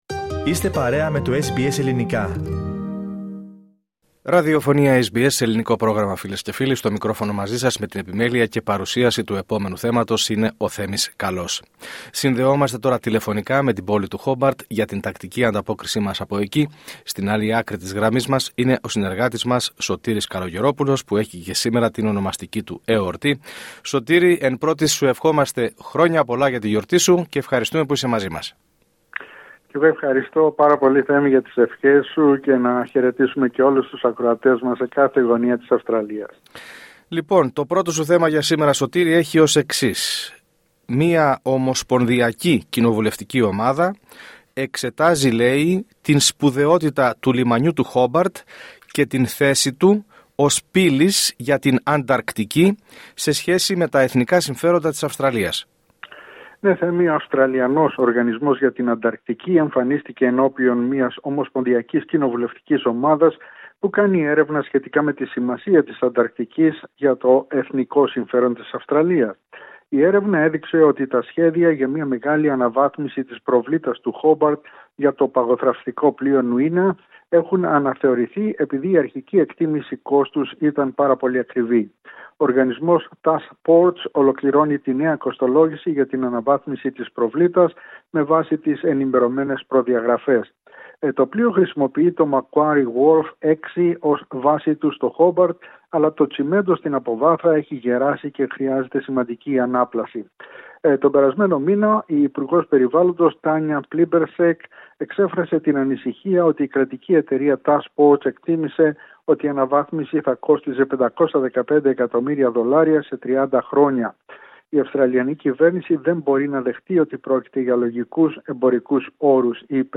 Ακούστε την εβδομαδιαία ανταπόκριση από την Τασμανία